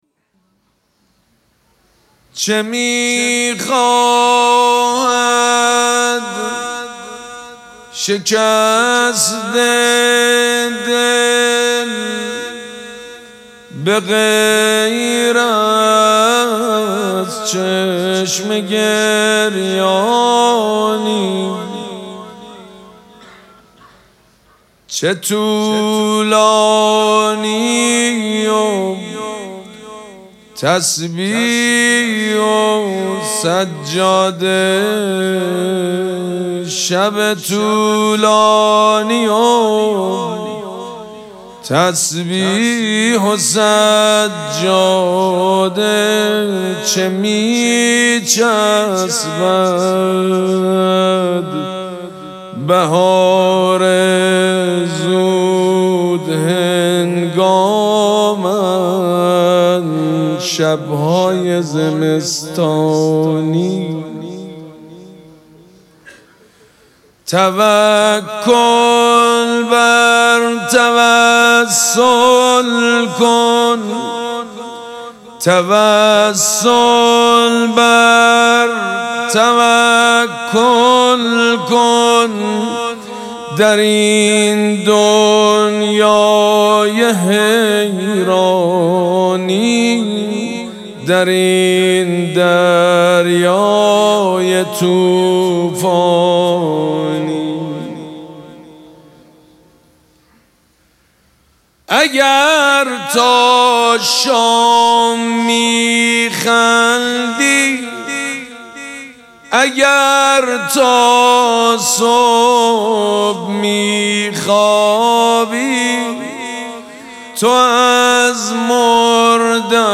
خیمه گاه - حاج سيد مجید بنی فاطمه - چه می خواهد شکسته دل به غیر از چشم گریانی - شعرخوانی شب پنجم مراسم فاطمیه 1403 - سید مجید بنی فاطمه
دانلود فایل صوتی مداحی شعرخوانی روضه ای جدید فاطمیه 1403 سید مجید بنی فاطمه چه می خواهد شکسته دل به غیر از چشم گریانی